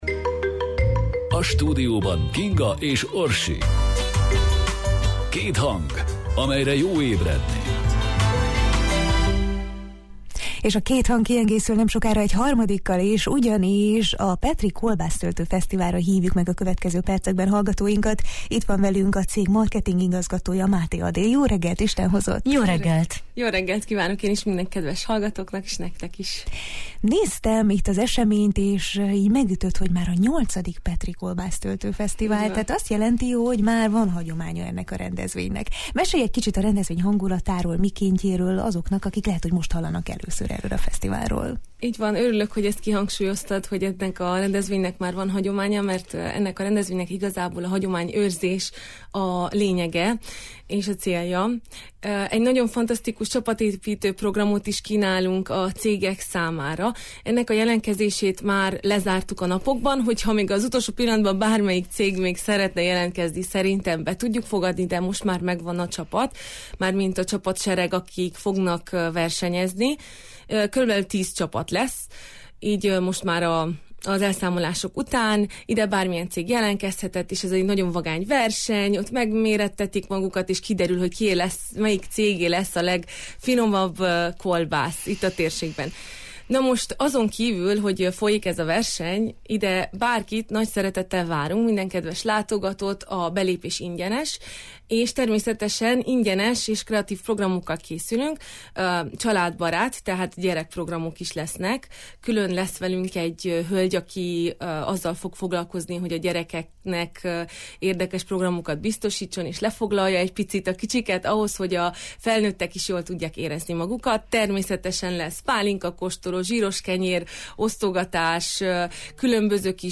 A VIII. Petry Kolbásztöltő Fesztivál ismét lehetőséget teremt arra, hogy kiderüljön, melyik csapat készíti Erdély legízletesebb kolbászát.